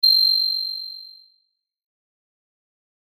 キーン
/ F｜演出・アニメ・心理 / F-15 ｜ワンポイント キラーン_キラキラ_ok
キィーン